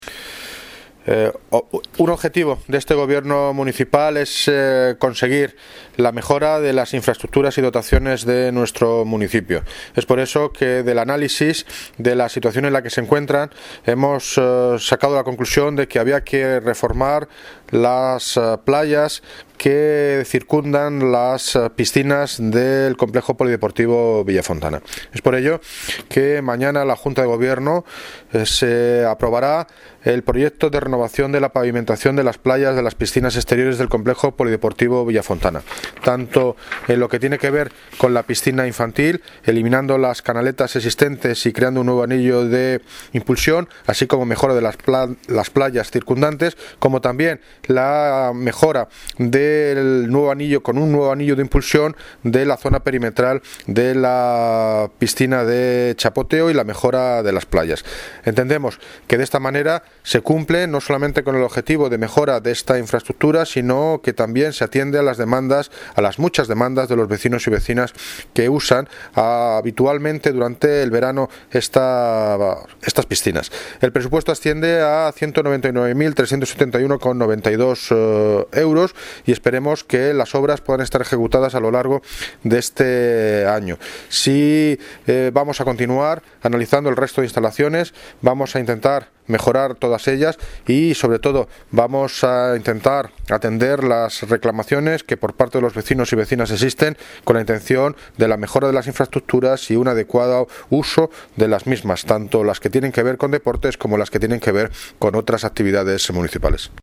Audio - David Lucas (Alcalde de Móstoles) Sobre Proyecto Reforma Polideportivo Villafontana